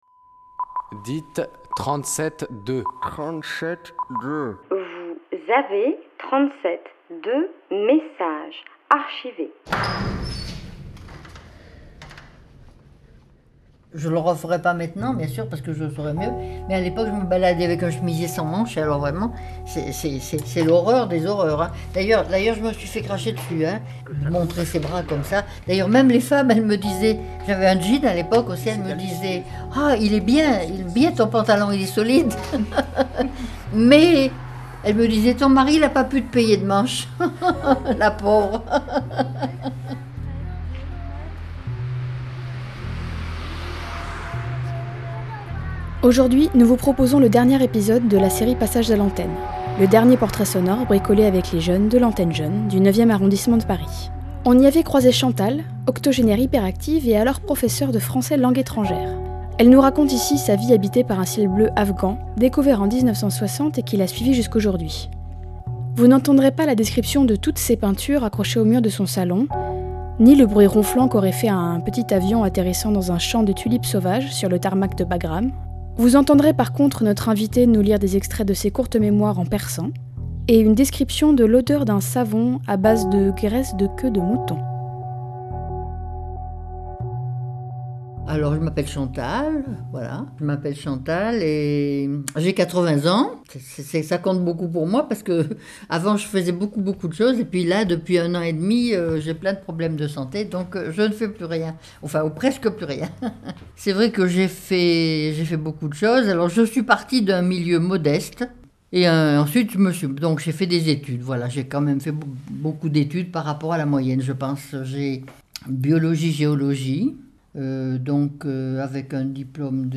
Le 10 mai, nous vous proposons la rediffusion de cet épisode de la série "Passages à l'antenne", le dernier portrait sonore bricolé avec les jeunes de l'Antenne Jeune du 9e arrondissement de Paris en mai 2015.